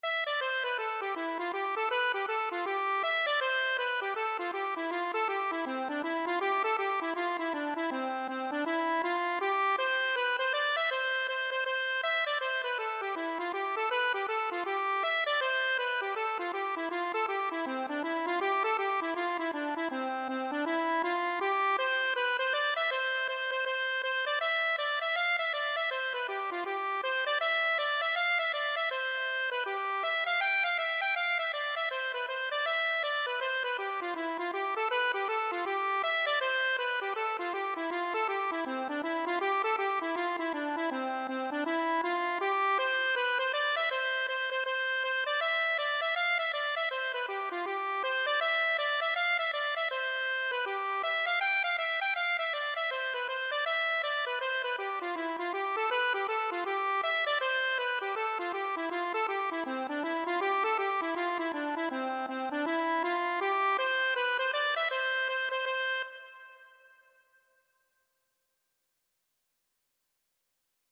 C major (Sounding Pitch) (View more C major Music for Accordion )
C5-G6
4/4 (View more 4/4 Music)
Accordion  (View more Easy Accordion Music)
Traditional (View more Traditional Accordion Music)